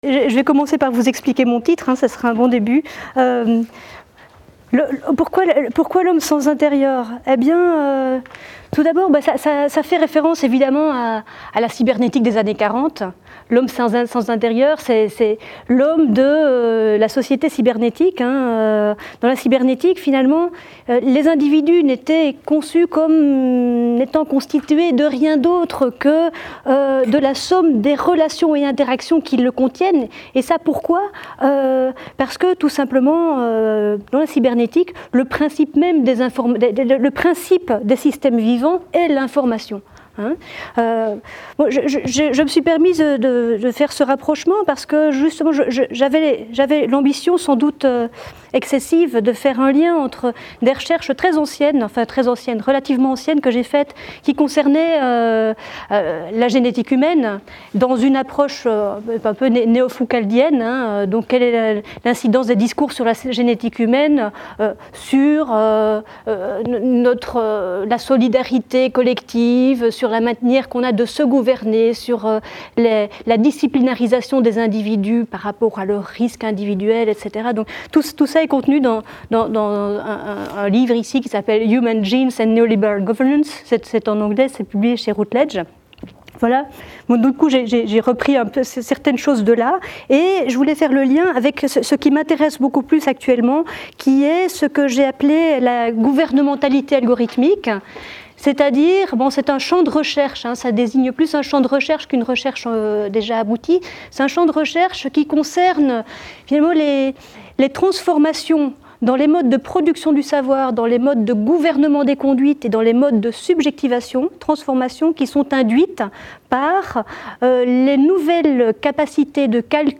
Intervention au séminaire formes de surveillance en médecine et santé publique.